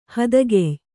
♪ hadagey